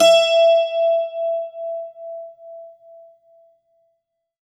STRINGED INSTRUMENTS
52-str13-bouz-e4.wav